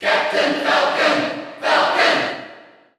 Category: Crowd cheers (SSBU) You cannot overwrite this file.
Captain_Falcon_Cheer_Dutch_SSBU.ogg.mp3